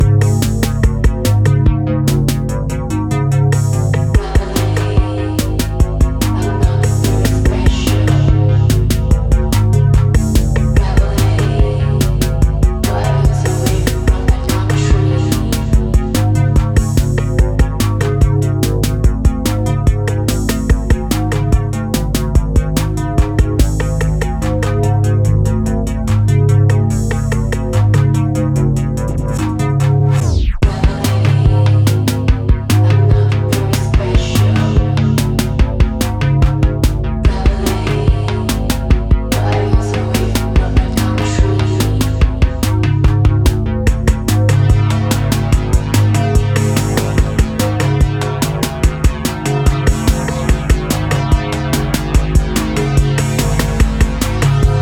” a track structured in Synth Pop
crystal-clear voice